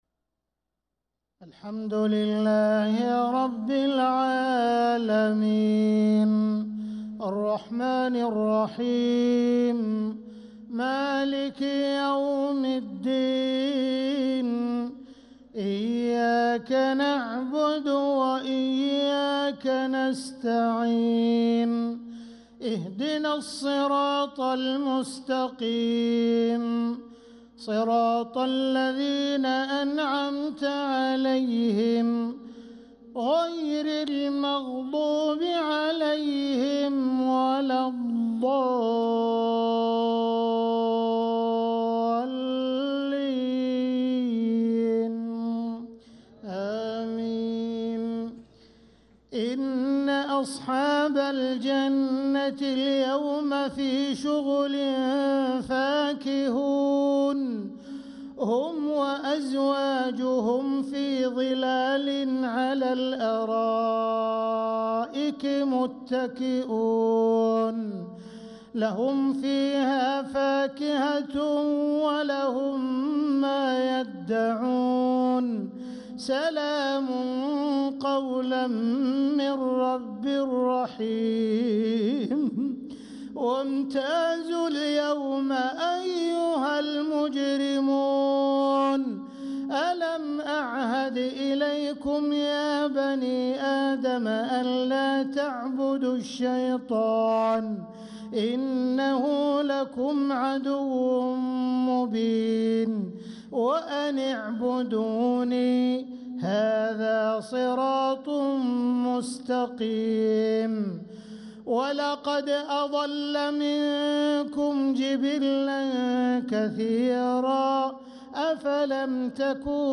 صلاة العشاء للقارئ عبدالرحمن السديس 14 رجب 1446 هـ
تِلَاوَات الْحَرَمَيْن .